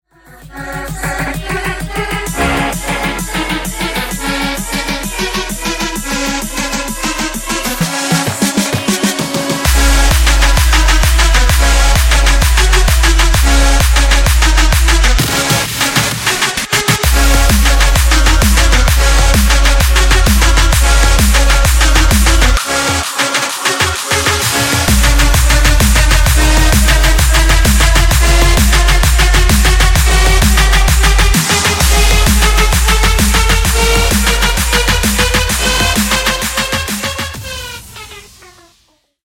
Электроника
клубные